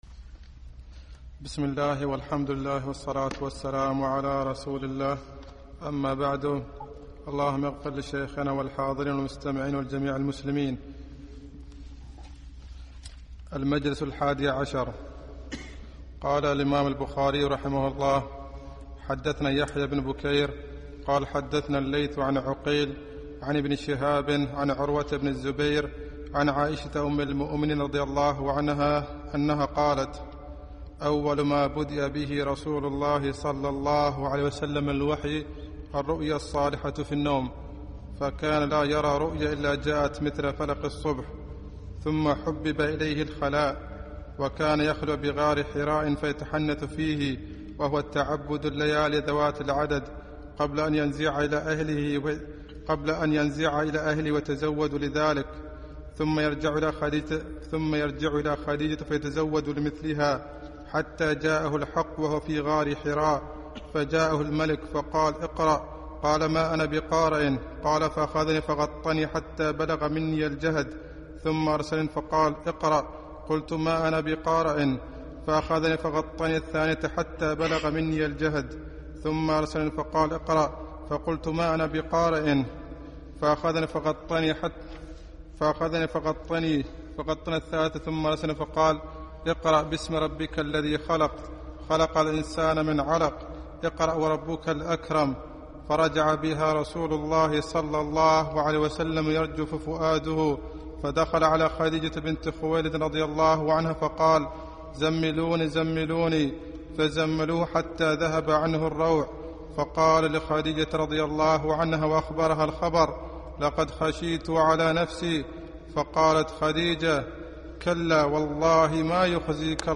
الدرس الحادي عشر